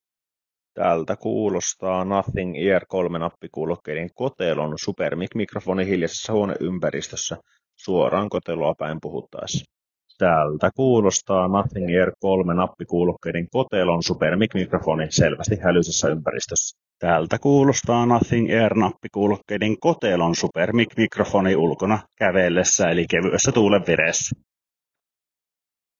Super Mic -mikrofonin ääniesimerkki
Kuulokkeiden kotelon Super Mic -mikrofoni tuottaa sisätiloissa ja ulkona kävellessä varsin hyvää ääntä, joka on pykälän luonnollisempaa kuin kuulokkeiden. Erityisesti kotelo nousee edukseen valmistajankin mainostamassa tilanteessa, jossa ympäristössä on selvää hälinää, jolloin kotelon mikrofoni säilyy edelleen pääasiassa luonnollisena. Hälyisässä ympäristössä ääneen syntyi jo jonkin verran digitaalista virheääntä ja ikään kuin kaikuna läpi mikrofoniin pääsevää ympäristön ääntä, eli sitä ei voi hiljaiseksi ympäristöksi käytännössä luulla, mutta laatu on kuitenkin tilanteeseen nähden erinomaista.
Nothing-Ear-3-Super-Mic-mikrofoni.wav